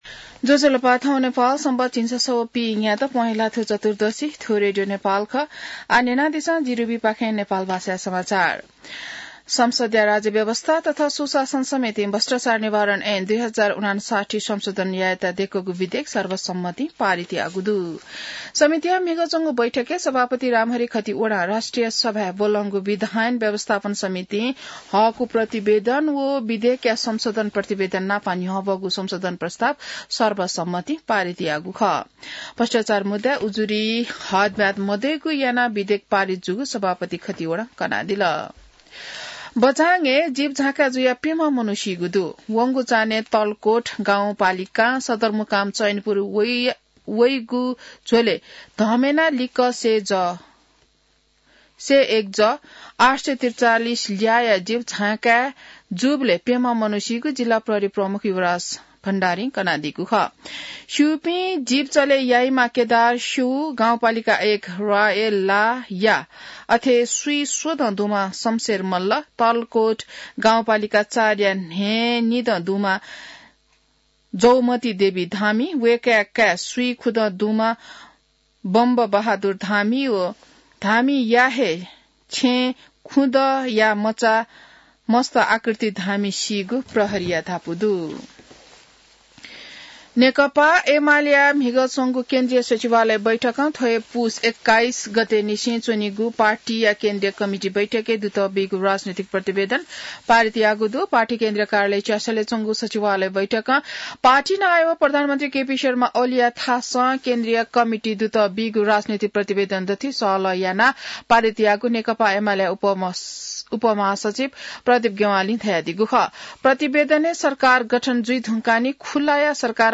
नेपाल भाषामा समाचार : २० पुष , २०८१